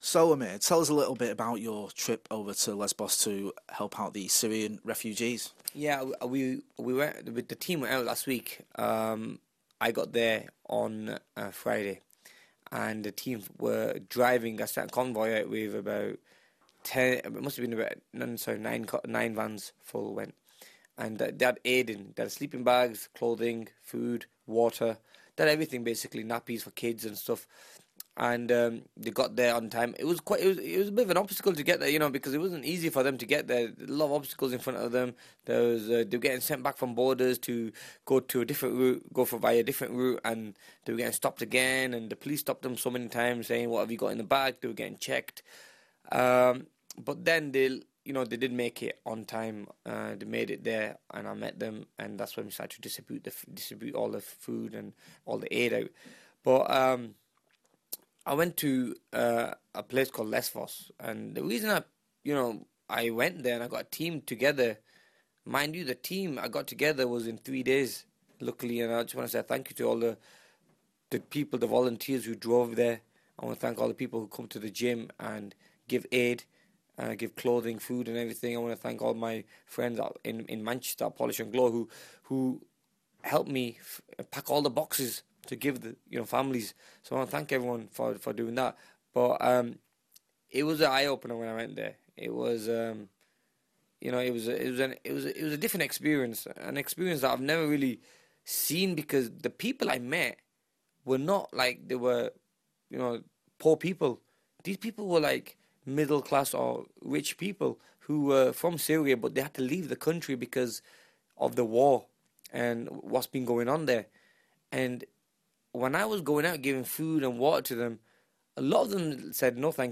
Boxer speaks about his Lesbos trip